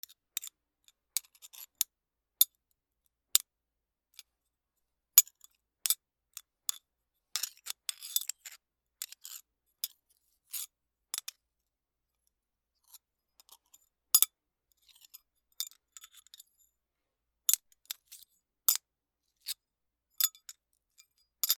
/ M｜他分類 / L01 ｜小道具 / 文房具・工作道具
プレパラート 『チャリ』